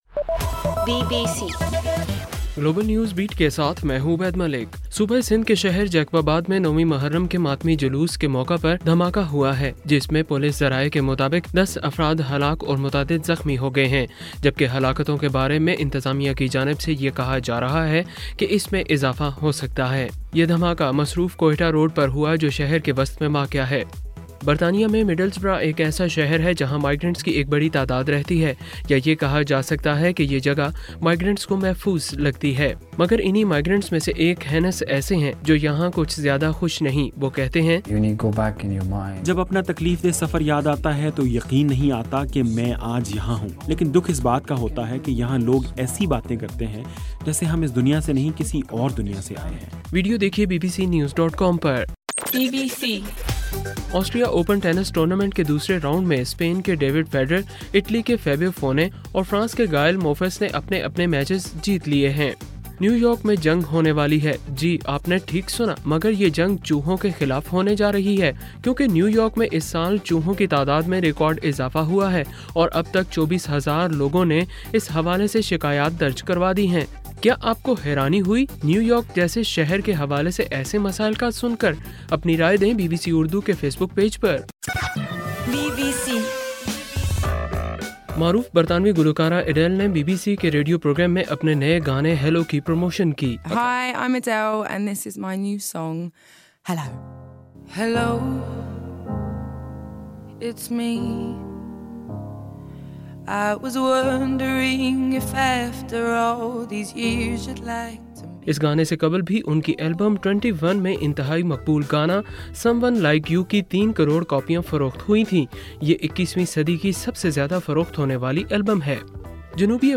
اکتوبر 23: رات 10 بجے کا گلوبل نیوز بیٹ بُلیٹن